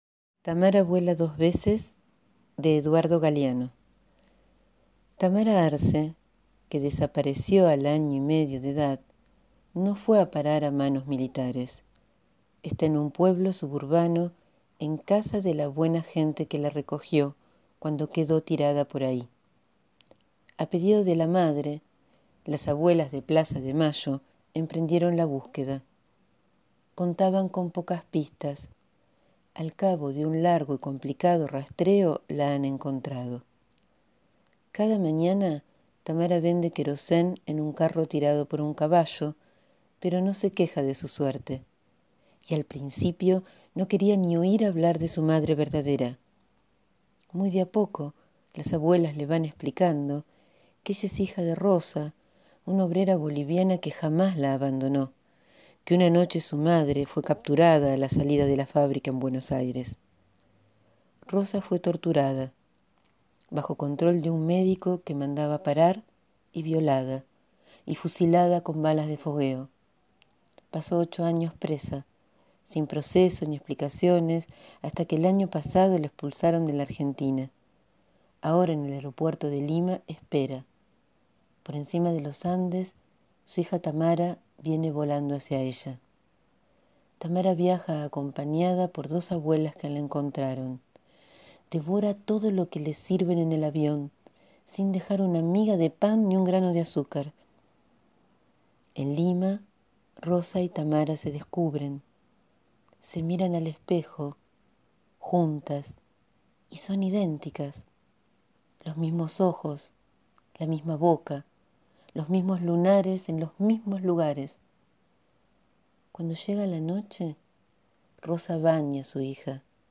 Acompañamos este 24 de marzo con la lectura de «Tamara vuela dos veces» de Eduardo Galeano (1940-2015).